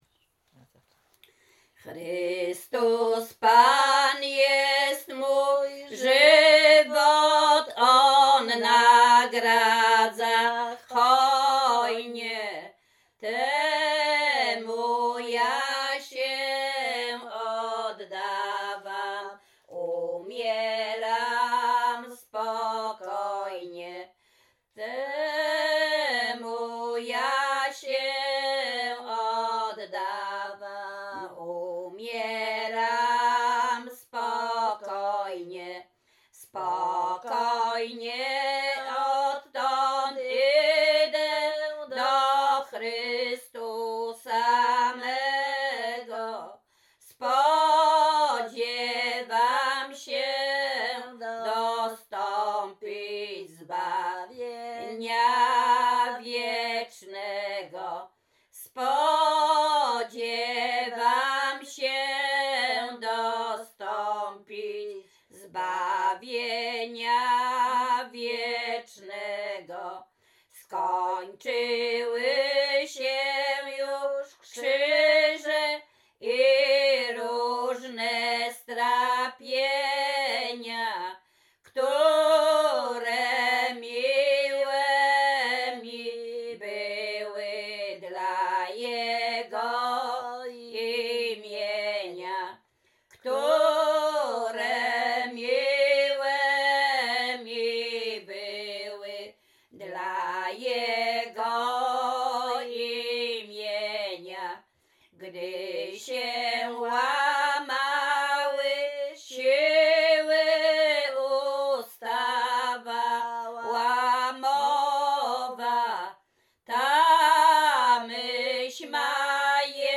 Śpiewaczki z Czerchowa
genre Pogrzebowa
Tags Array nabożne katolickie pogrzebow